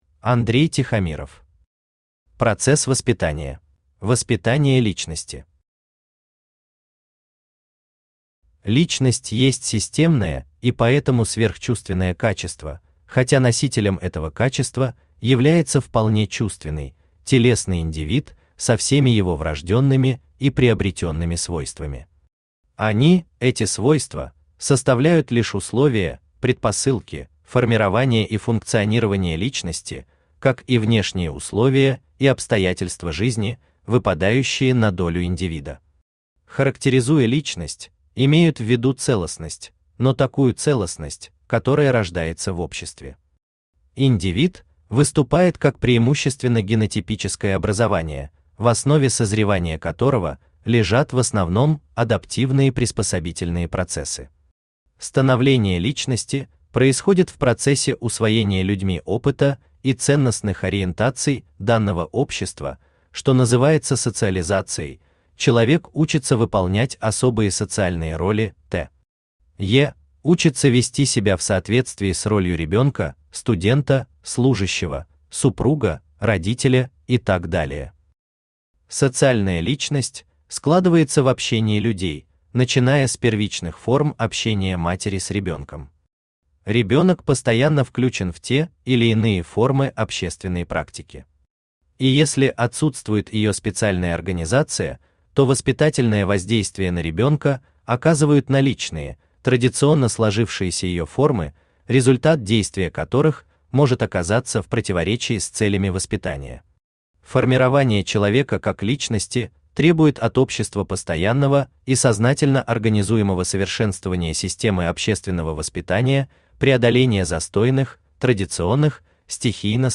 Аудиокнига Процесс воспитания | Библиотека аудиокниг
Aудиокнига Процесс воспитания Автор Андрей Тихомиров Читает аудиокнигу Авточтец ЛитРес.